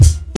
Kick (77).wav